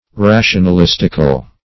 rationalistical - definition of rationalistical - synonyms, pronunciation, spelling from Free Dictionary
rationalistical.mp3